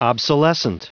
Prononciation du mot obsolescent en anglais (fichier audio)
Prononciation du mot : obsolescent